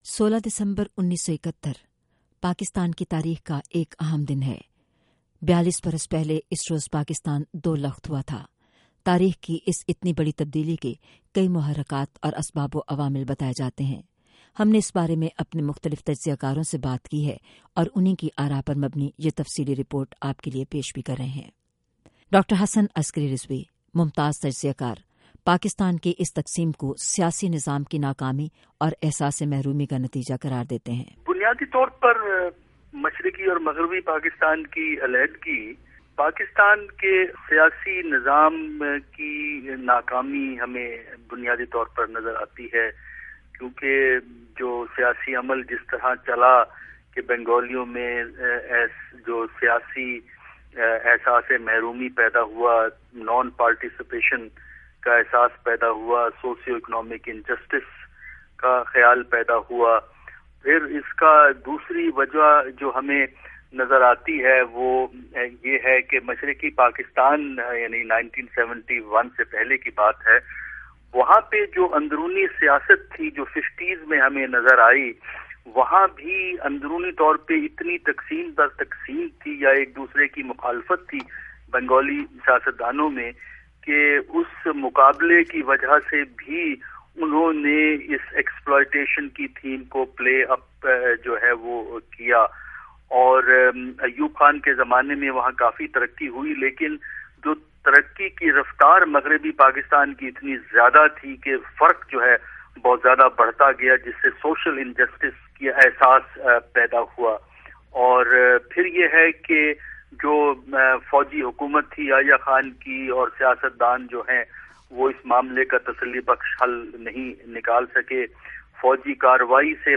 وائس آف امریکہ کی اِس خصوصی رپورٹ میں ممتاز تجزیہ کاروں نےپاکستان کے ٹوٹنے کے تاریخی و سیاسی پسِ منظر کا تفصیلی ذکر کیا۔